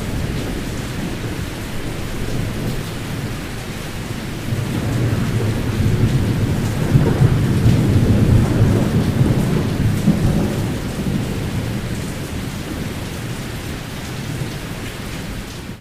rain_stormy.ogg